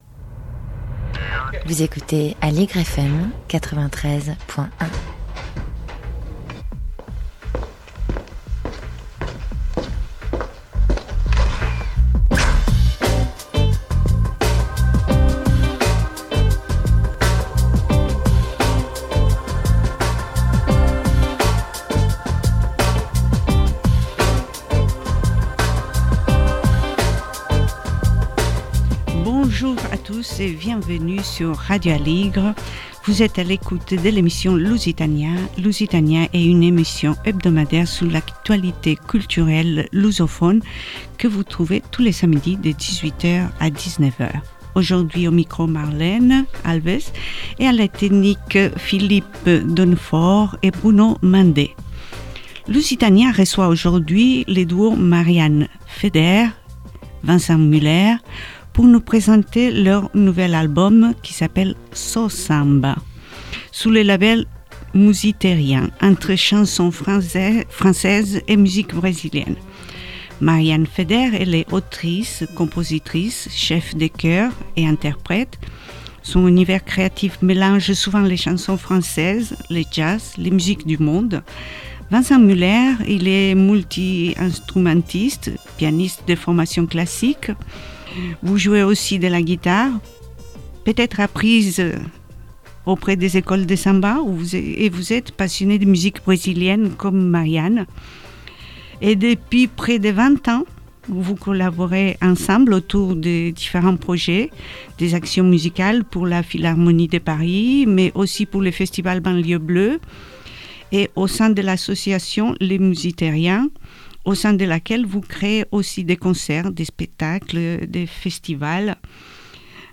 Dans leur nouvel album ils mélangent chanson française et musique brésilienne, ils nous font voyager entre Paris et Salvador.